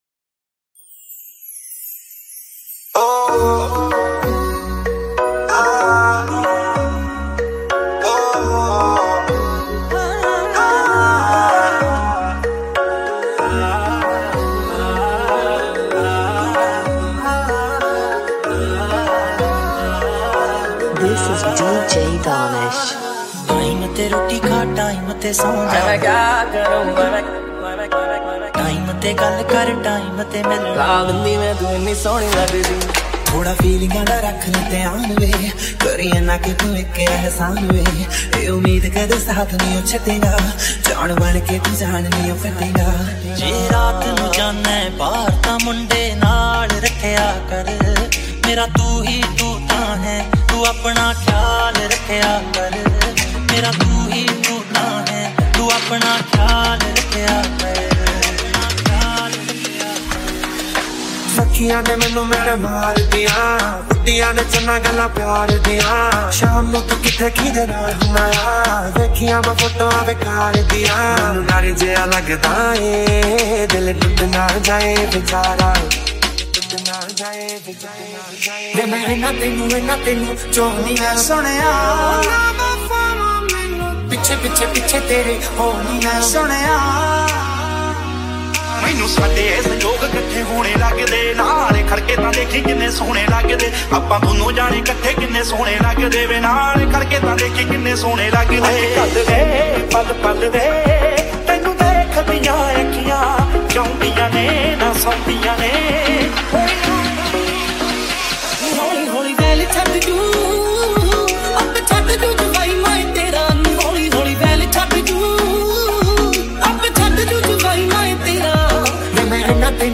Best Punjabi Mashup